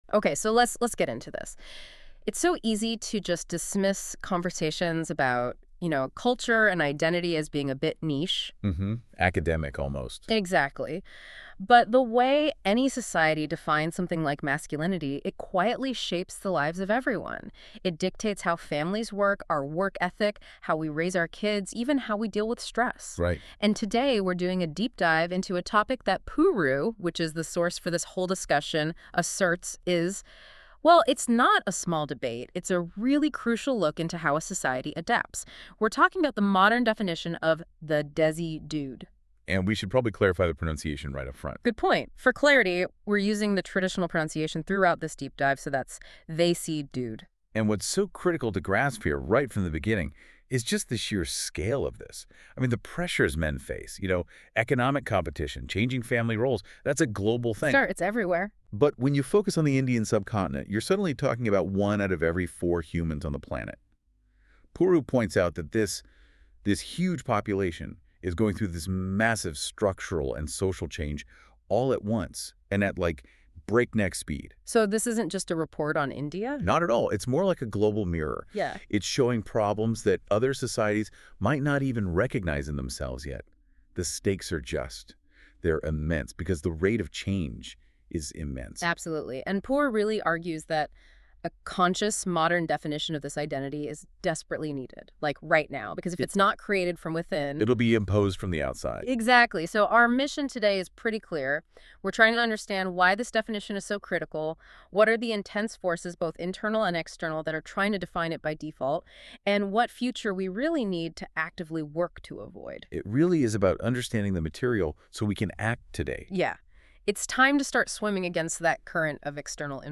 Here’s an AI‑made podcast version.